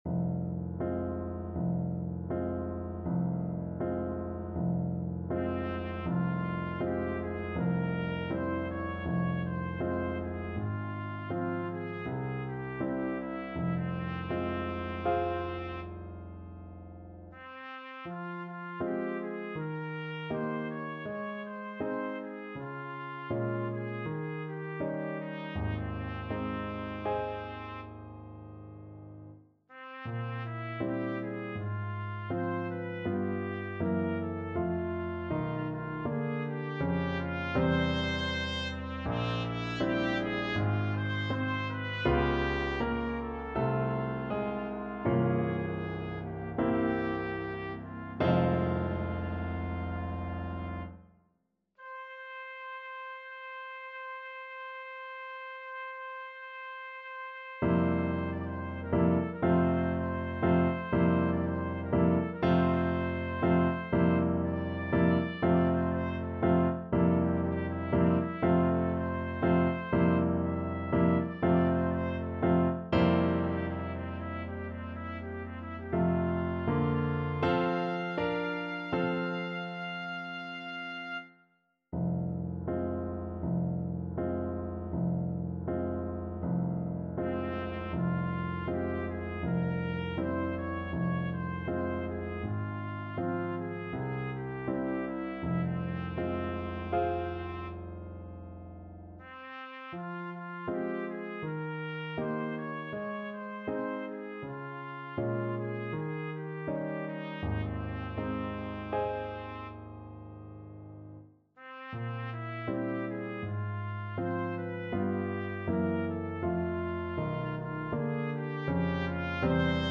Trumpet
4/4 (View more 4/4 Music)
Un poco andante
F minor (Sounding Pitch) G minor (Trumpet in Bb) (View more F minor Music for Trumpet )
Classical (View more Classical Trumpet Music)